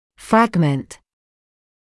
[‘frægmənt][‘фрэгмэнт]фрагмент; часть